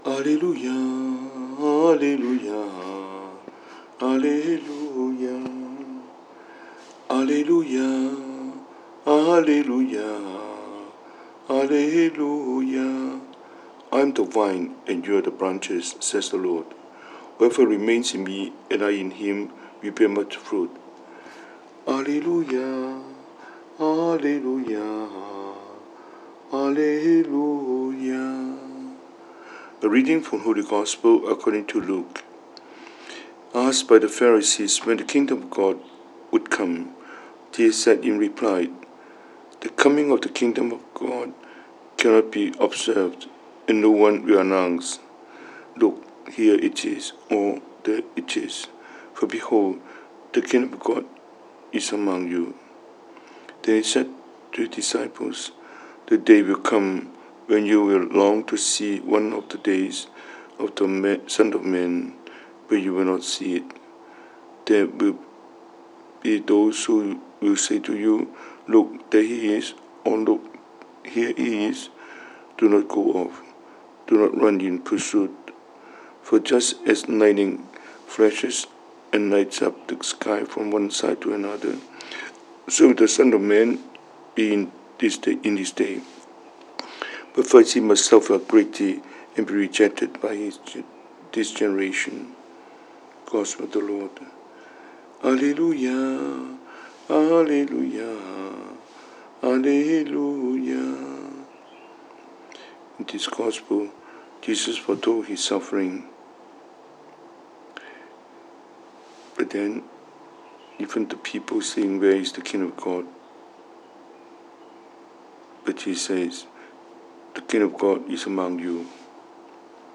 Cantonese Homily,